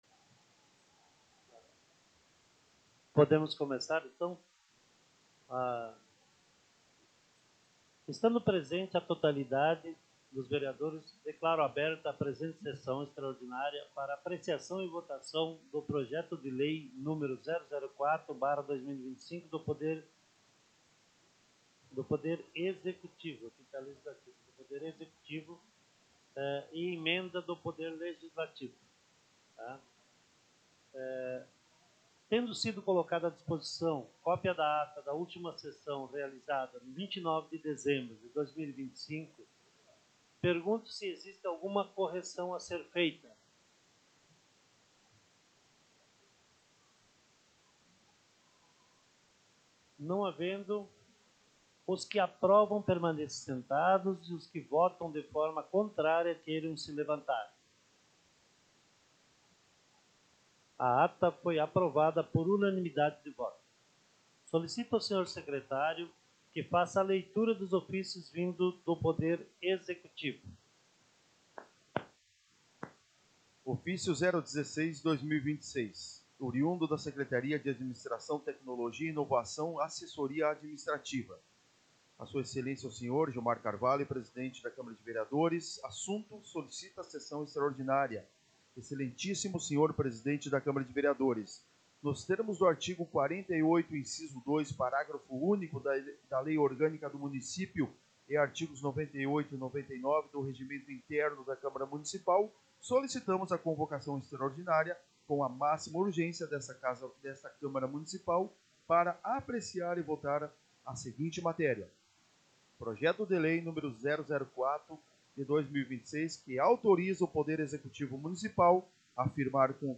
Áudio Sessão Extraordinária 30.01.2026